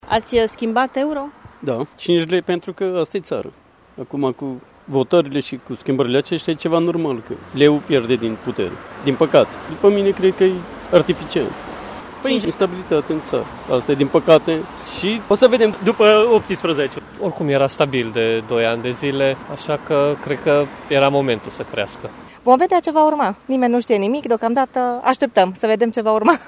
Târgumureșenii sunt rezervați și speră ca stabilitatea să revină după 18 mai: